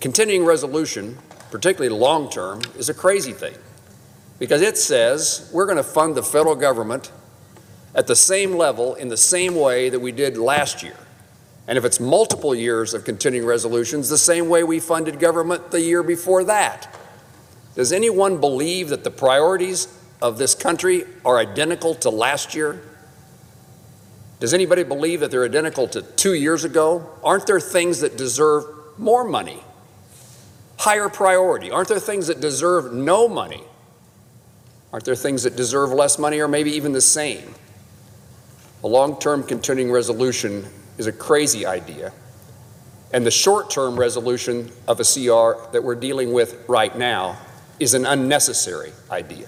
Moran says a shutdown means “uncertainty” and “dysfunction.” He didn’t name Democrats in his speech on the Senate floor, but he says some in Congress want to address other matters with the continuing resolution.